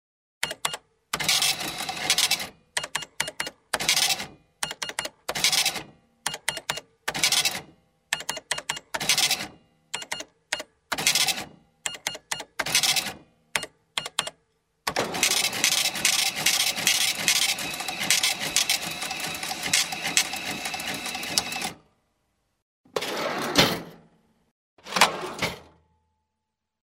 Electronic cash register, receipt printout 2
Sound category: Money, coins